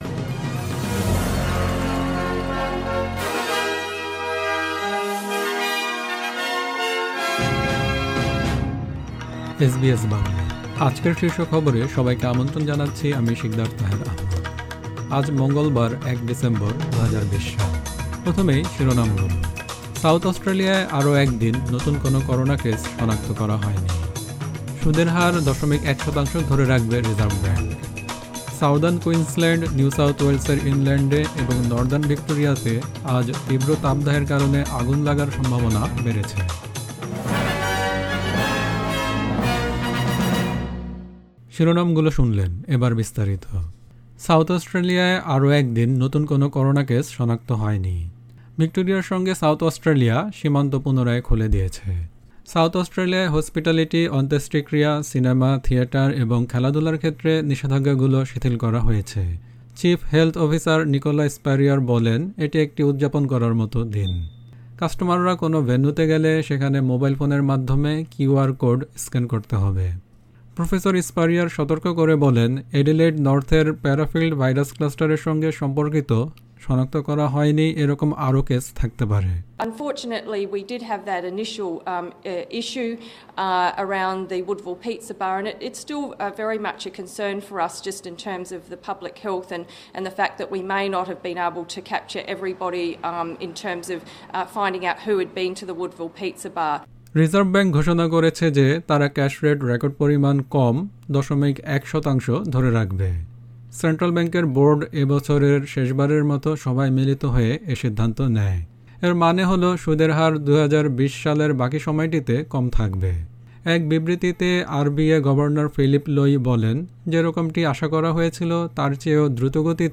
এসবিএস বাংলা শীর্ষ খবর: ১ ডিসেম্বর ২০২০